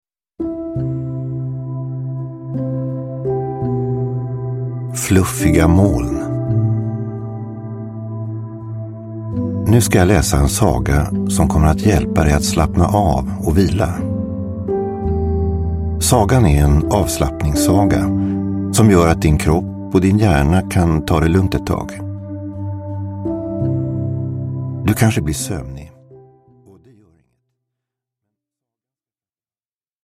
Avslappningssagor. Fluffiga moln – Ljudbok – Laddas ner